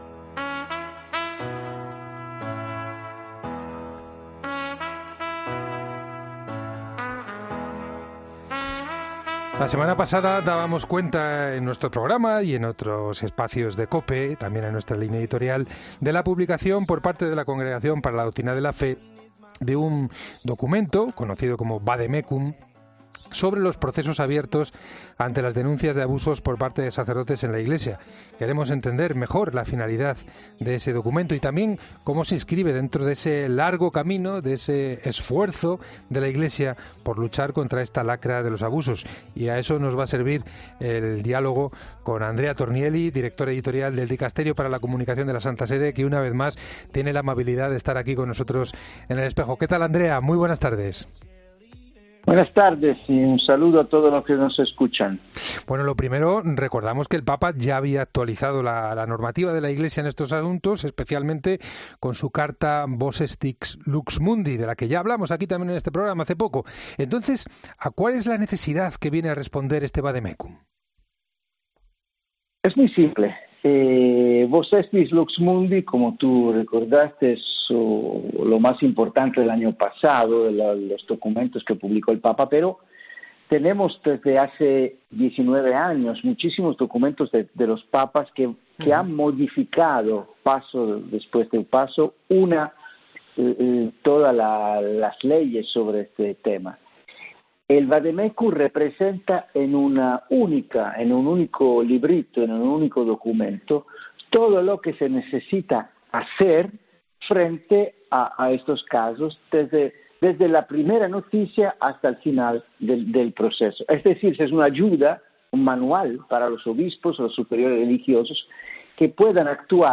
El Director Editorial del Dicasterio para la Comunicación de la Santa Sede analiza los puntos más importantes del Vademecum que publica la Santa Sede...